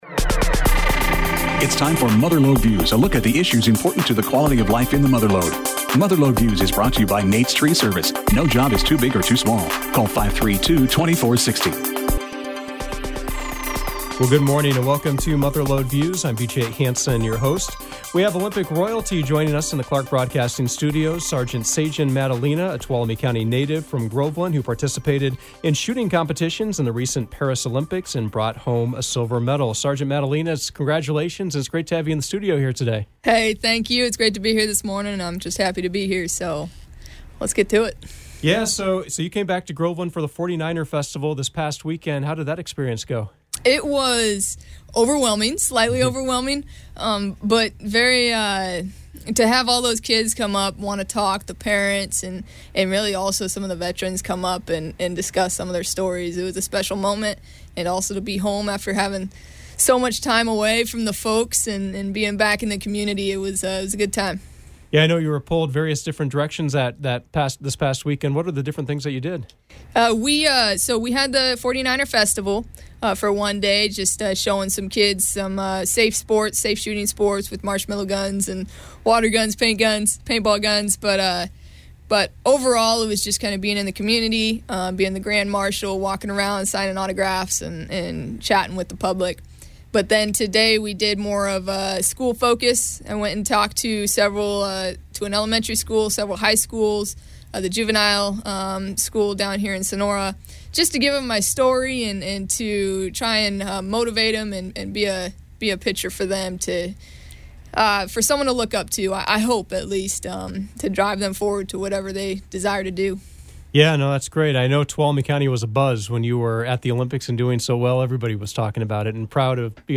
Mother Lode Views featured a conversation with Groveland sharpshooter Sagen Maddalena who took home a silver medal from the 2024 Paris Olympics. She spoke about growing up in Tuolumne County, how she became interested in shooting, her opportunity to compete at the collegiate level, later joining the US Army, and eventually reaching the pinnacle of the sport and taking part in two Olympic games.